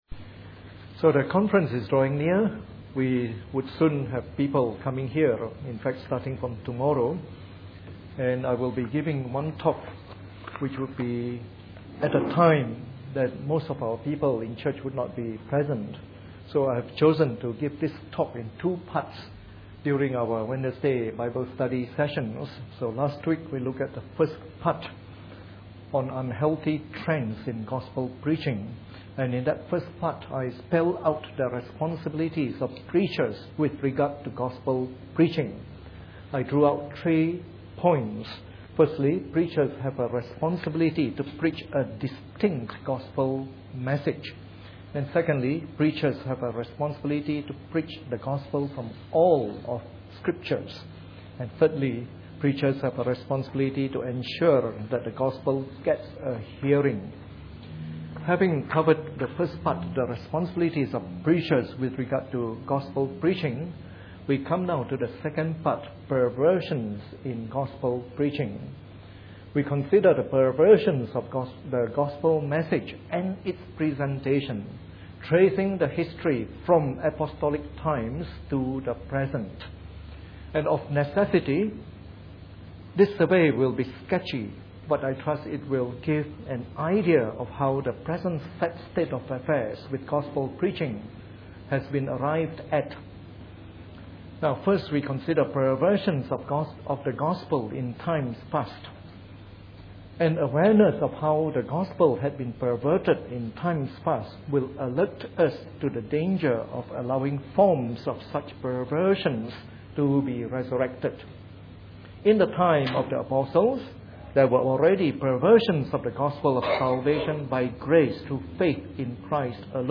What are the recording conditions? Preached on the 31st of August 2011 during the Bible Study.